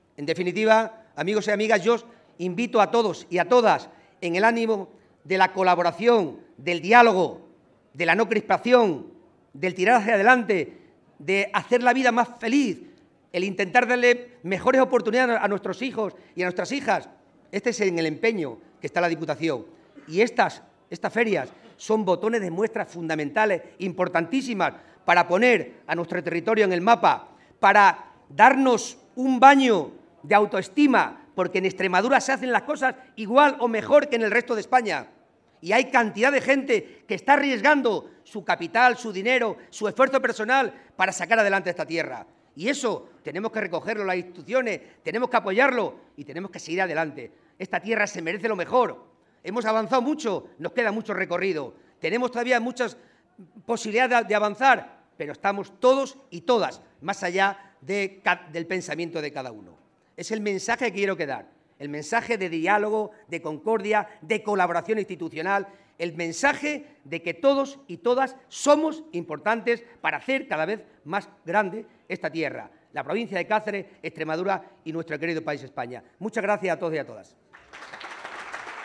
El presidente de la Diputación de Cáceres ha intervenido en el acto inaugural de la IX Feria de Apicultura y Turismo de Las Hurdes, donde ha lanzado un mensaje claro “de diálogo, de concordia, de colaboración institucional, el mensaje de que todos y todas somos importantes para hacer cada vez más grande esta tierra”
CORTES DE VOZ